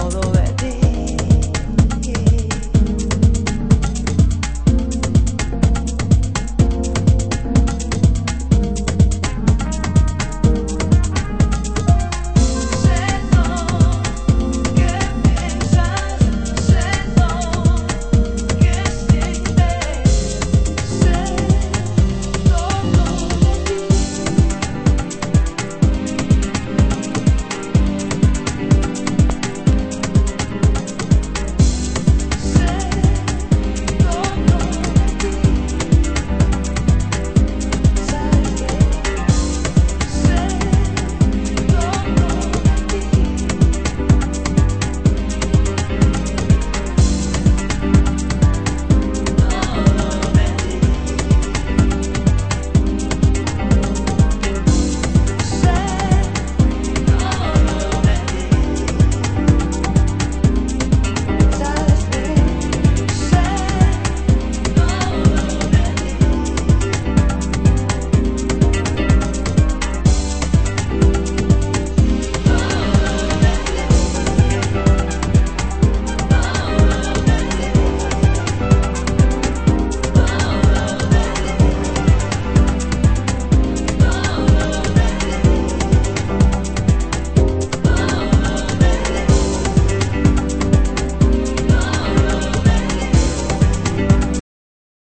盤質：盤面良好ですが少しチリノイズ有